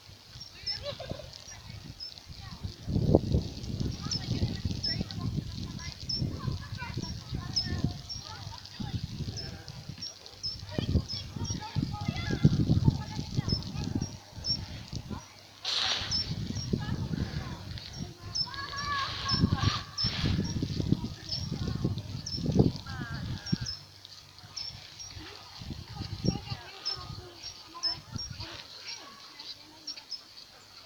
Agateador Europeo (Certhia brachydactyla)
Nombre en inglés: Short-toed Treecreeper
Condición: Silvestre
Certeza: Observada, Vocalización Grabada